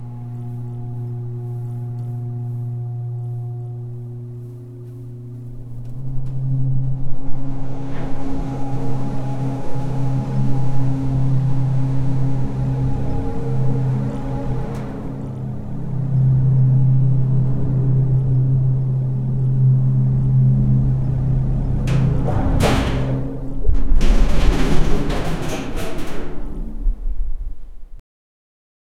rumbling-floor-as-an-indu-gxychvfp.wav